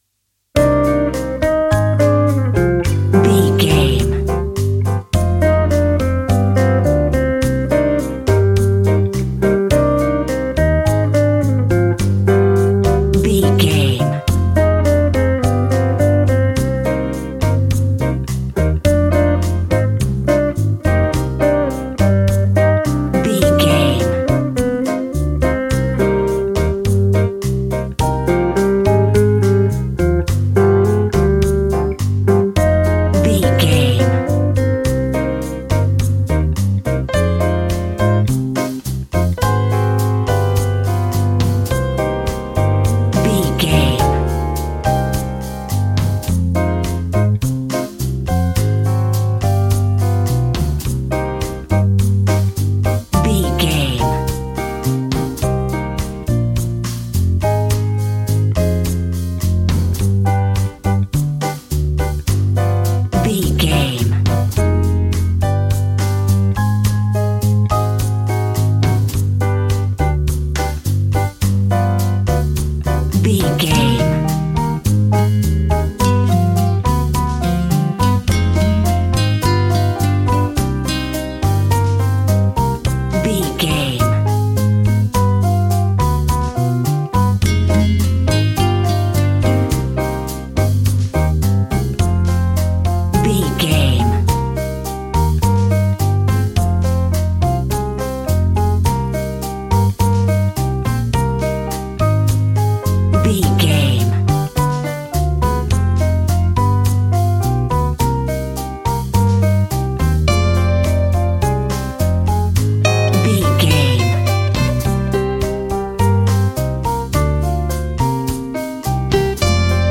An exotic and colorful piece of Espanic and Latin music.
Aeolian/Minor
funky
energetic
romantic
percussion
electric guitar
acoustic guitar